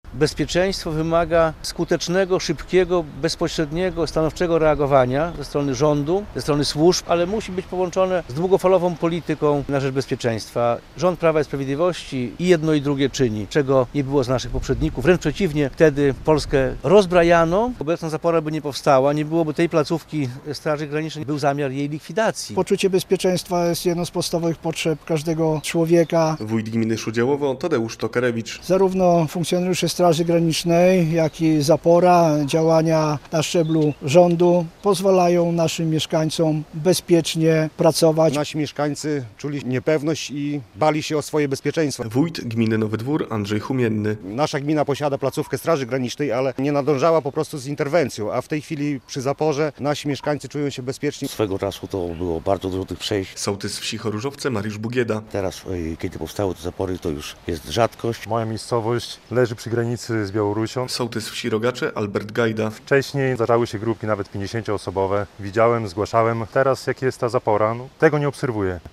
Jarosław Zieliński zorganizował konferencję przed siedzibą Straży Granicznej w Krynkach.